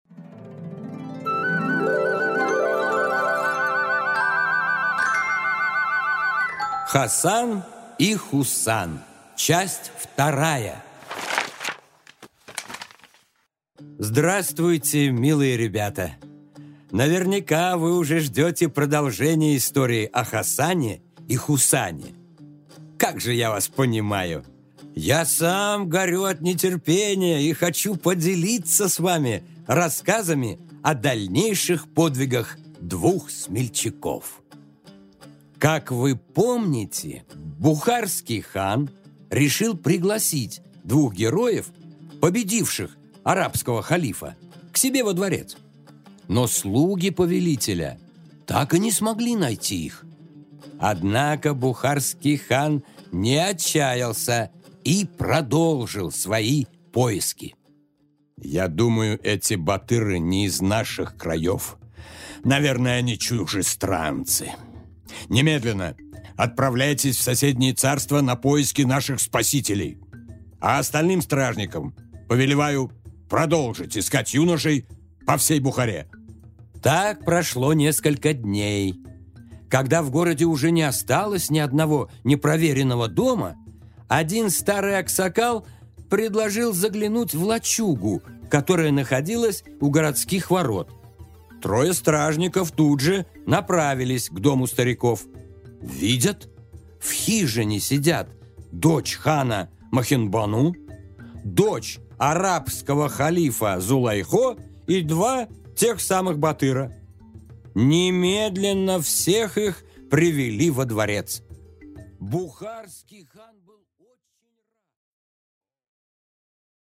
Аудиокнига Хасан и Хусан 2-часть